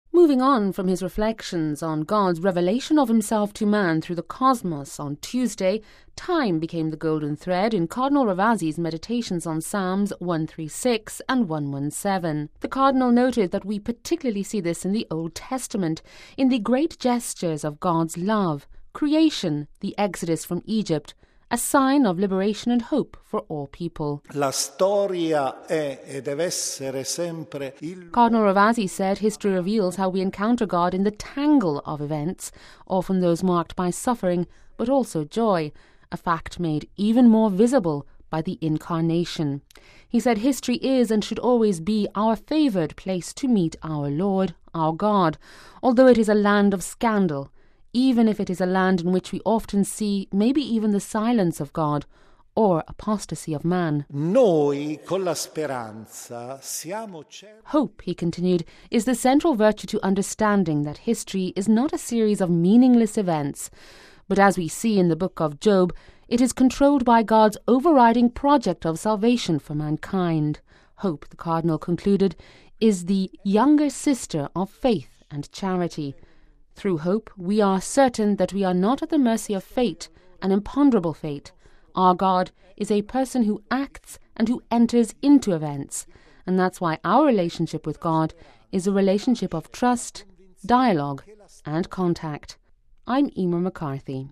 (Vatican Radio) History as a place of encounter with God, and the figure of the Messiah, as read through the Psalms. This was the central theme of the two meditations preached Tuesday morning, the third day of the Roman Curia’s Spiritual Exercises, led by Cardinal Gianfranco Ravasi, president of the Pontifical Council for Culture.